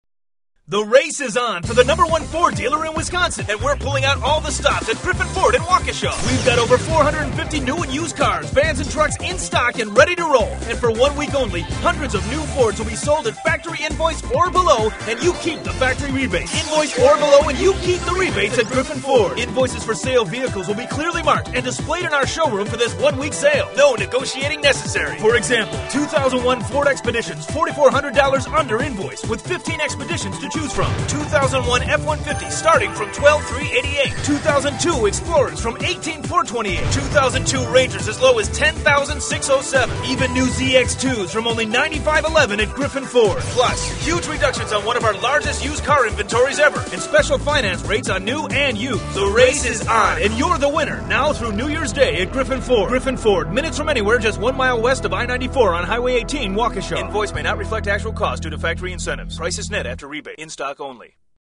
Griffin Ford Radio Commercial